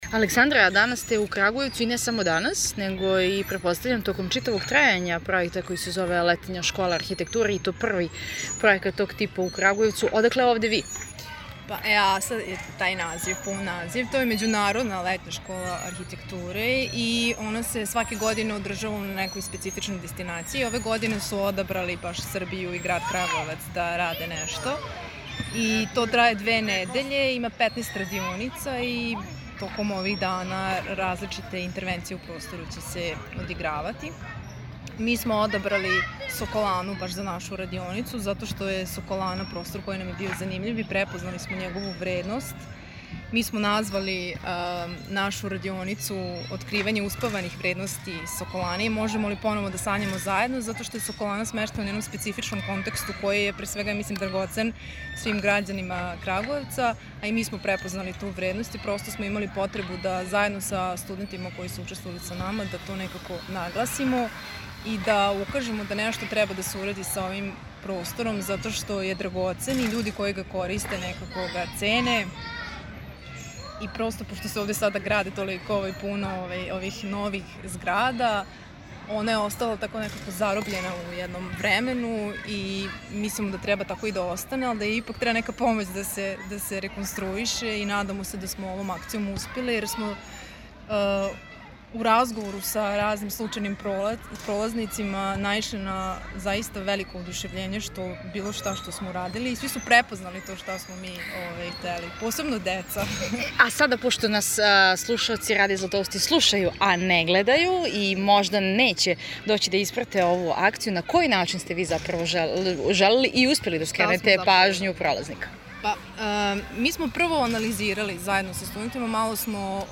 затичемо их испред зграде Соколане